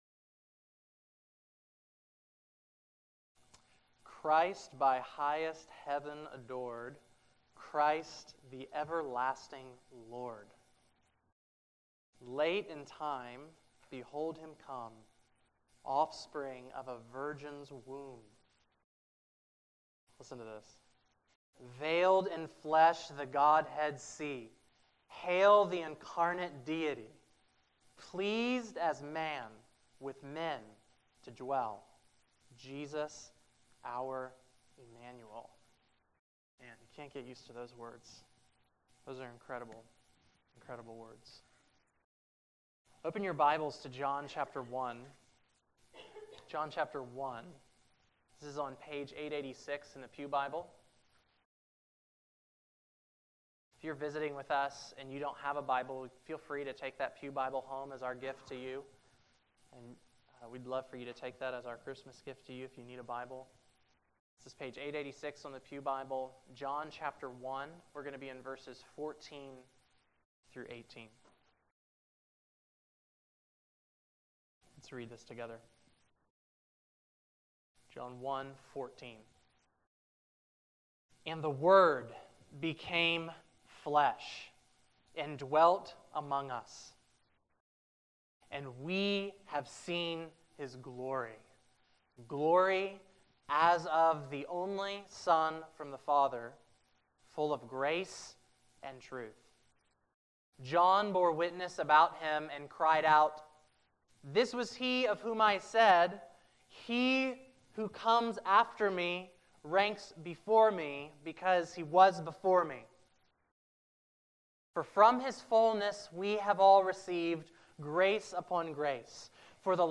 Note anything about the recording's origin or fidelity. December 25, 2016 Christmas Morning | Vine Street Baptist Church